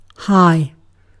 Ääntäminen
IPA : /haɪ/ US